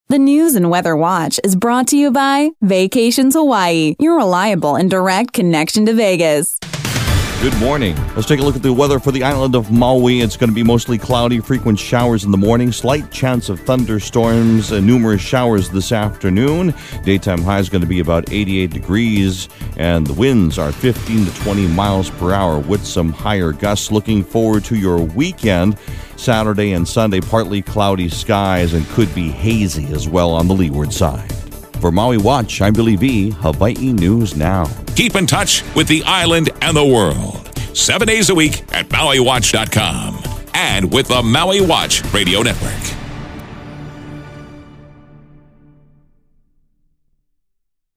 Your daily weather brief for August 7, 2015